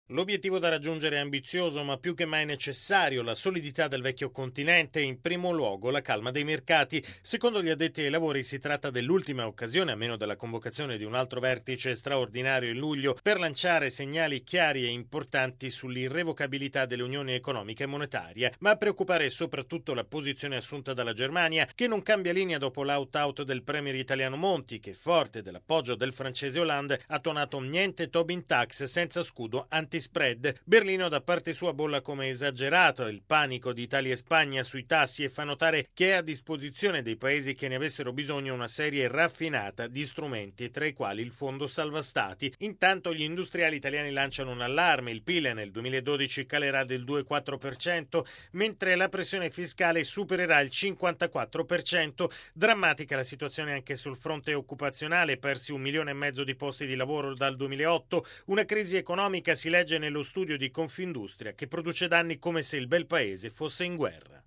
Radiogiornale del 28/06/2012 - Radio Vaticana